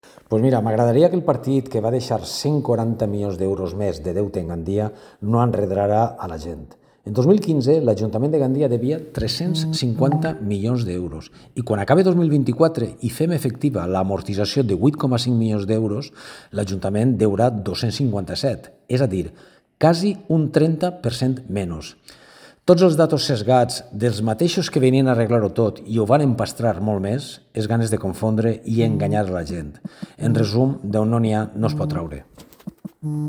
Ha sido el concejal de Economía y Hacienda en el Ayuntamiento de Gandia, Salvador Gregori Escrivá quien ha contestado a las críticas del PP recordando que ''dejaron 140 millones de más de deuda en Gandia'' y que le gustaría que no enredaran.
concejal Gregori (audio)